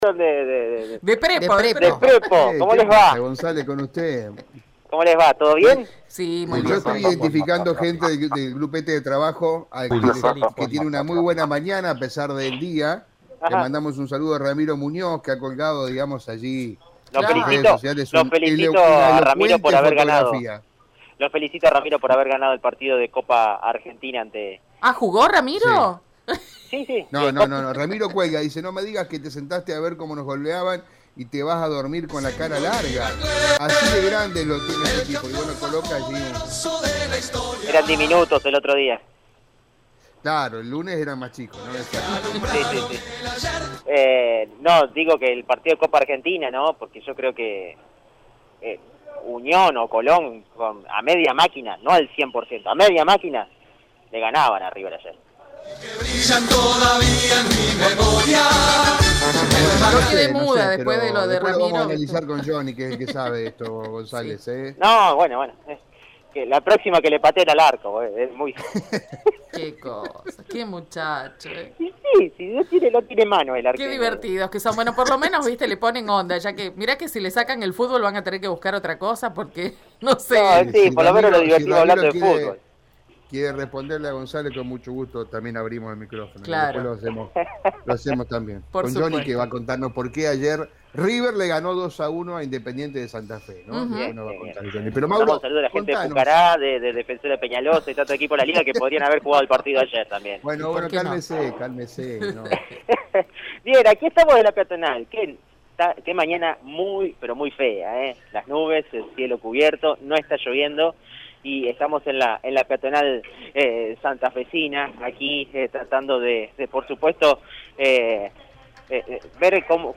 Según el móvil de Radio EME, los locales comerciales de la Peatonal San Martín de la ciudad capital abrieron sus puertas bajo la nueva modalidad.